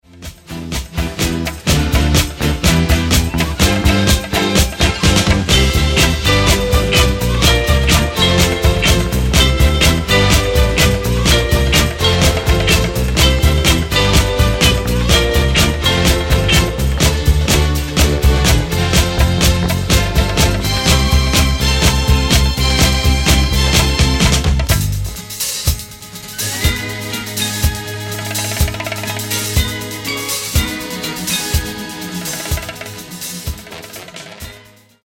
Genere:   Rare Disco Soul